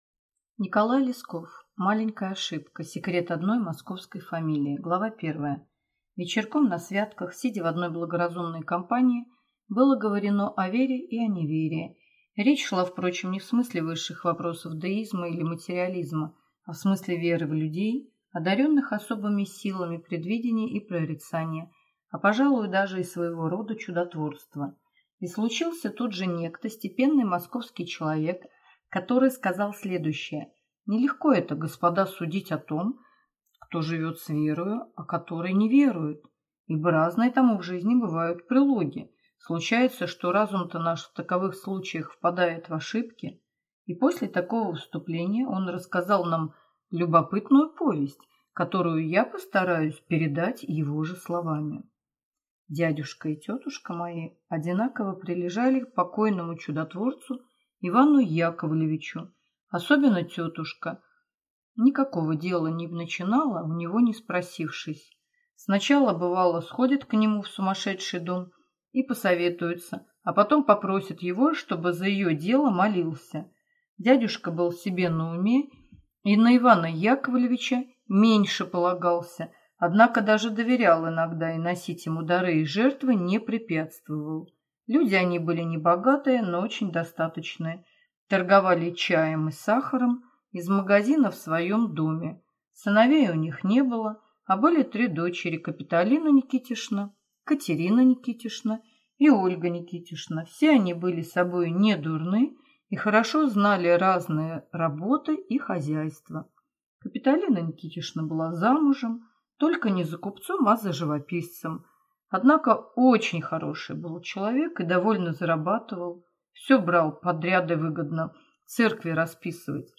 Аудиокнига Маленькая ошибка | Библиотека аудиокниг